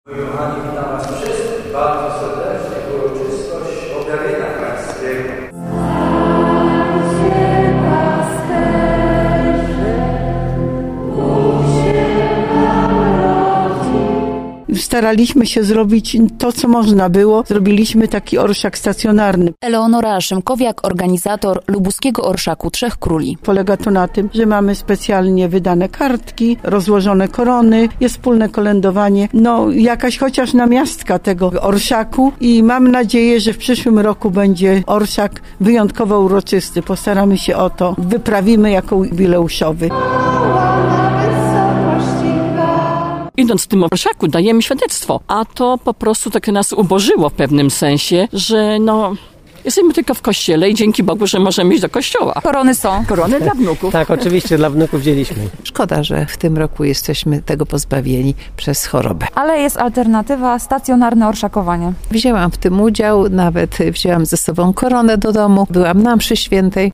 W kilku świątyniach na wiernych czekały dziś korony, a po mszy odbyło się wspólne kolędowanie. Tak było między innymi w kościele p.w. Podwyższenia Krzyża Świętego: